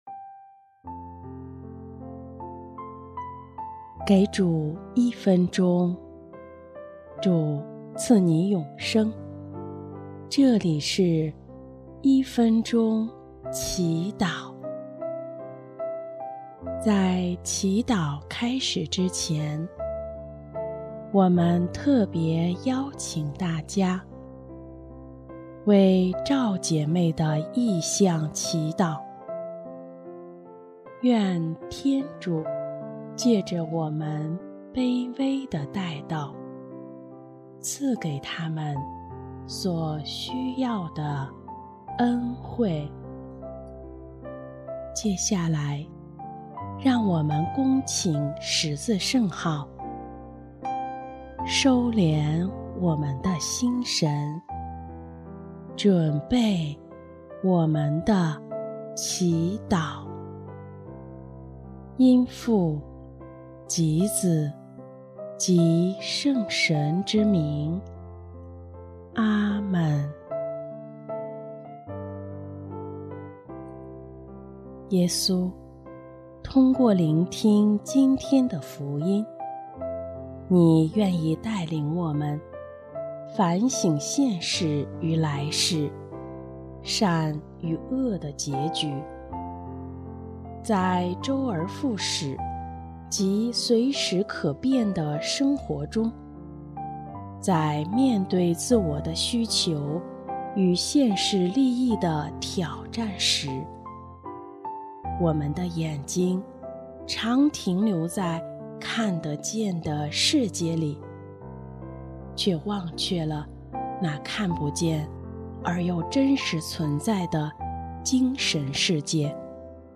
【一分钟祈祷】| 8月3日反省与勇气的结合，敢于迈向天国！